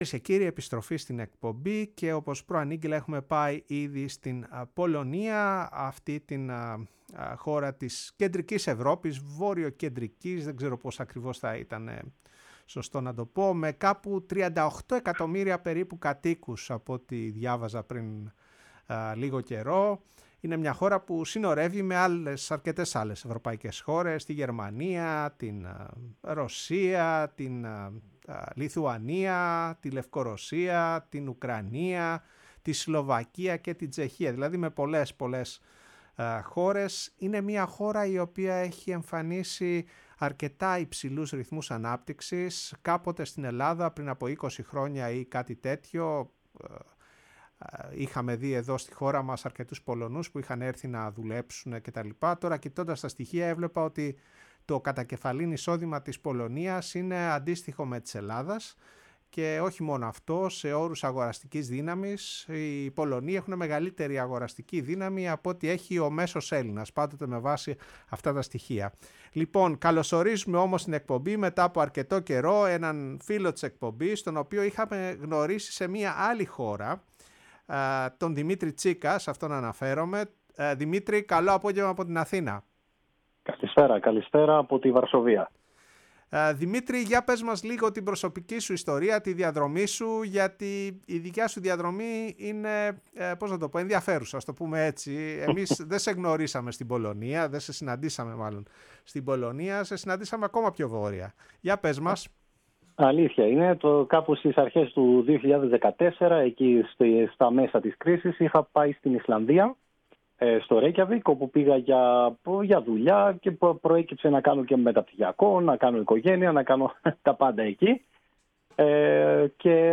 μιλώντας στο Ραδιόφωνο της Φωνής της Ελλάδας και στην εκπομπή “Η Παγκόσμια Φωνή μας”